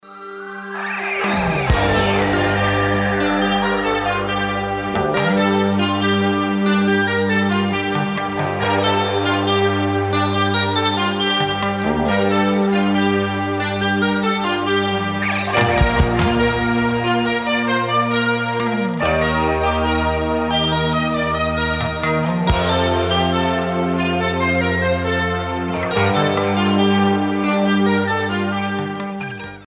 facevano uso massiccio di elettronica e sintetizzatori